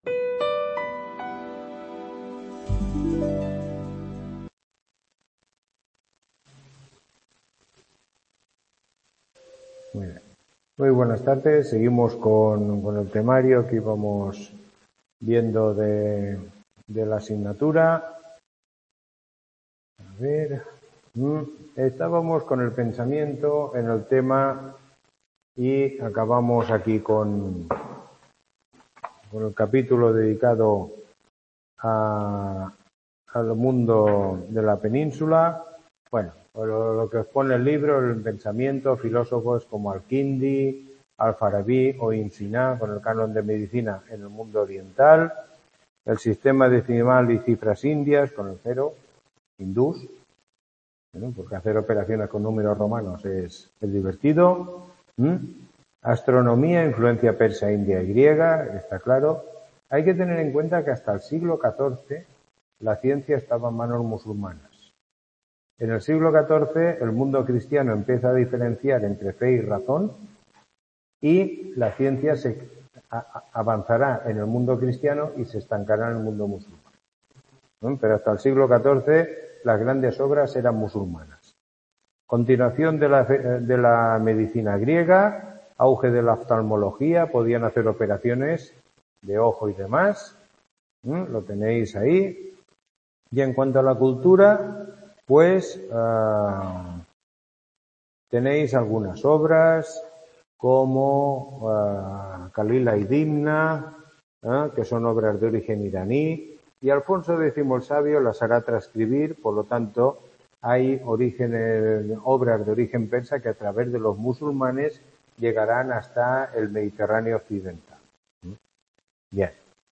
Tutoría 10